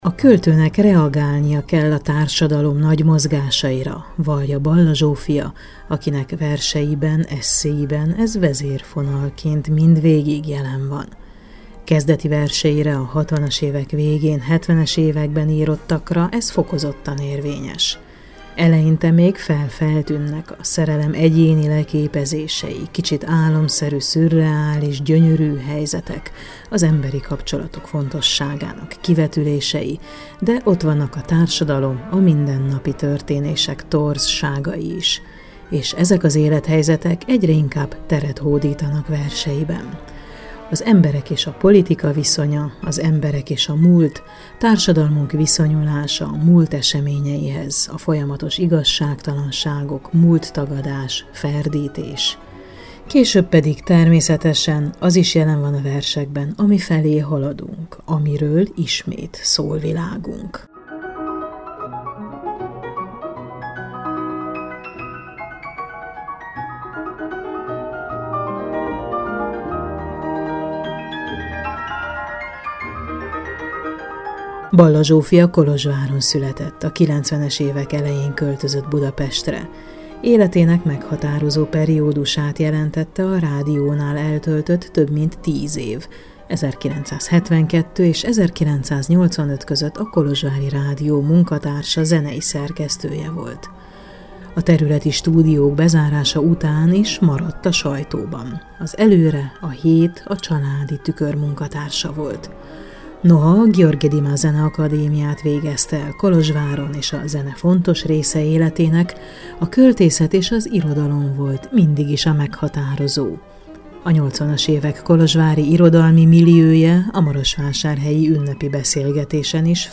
Marosvásárhelyen hetvenedik születésnapját ünnepelték a Bernády Házban, ahol Markó Béla és Kovács András Ferenc beszélgetett vele, életről, a múlt fontosságáról, sorsszerűségről, költészetről, nagy elődökről.